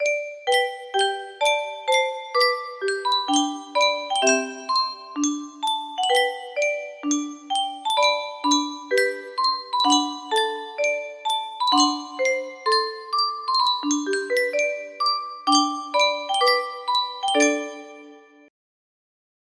Yunsheng Boite a Musique - La Marseillaise Y307 music box melody
Full range 60